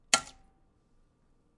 Sound Effects » Water Boiling Strong
描述：Boiling some water at strongstrength. Large bubbles emerging.Cracking sounds from the oven also included. Easy to mix into a loop.
标签： kitchen boil strong cracking warm hot boiling water bubbling oven highquality cooking high
声道立体声